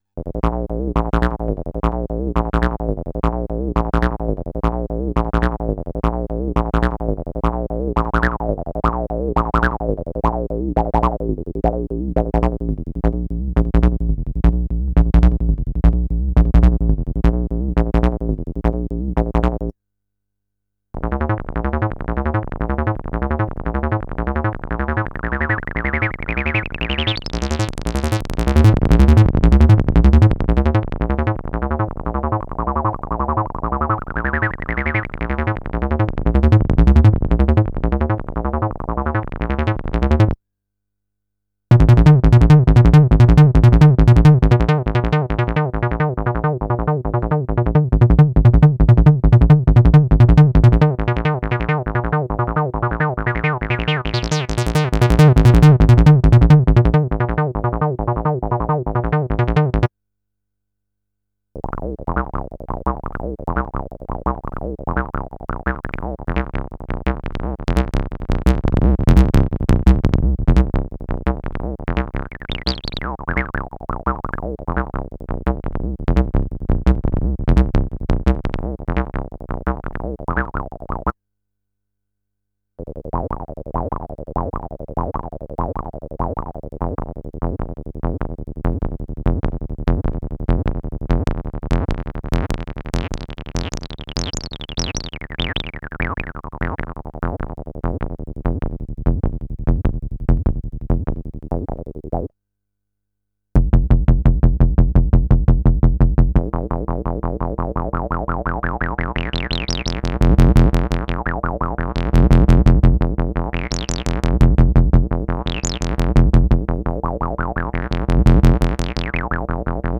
41 TB 303.wav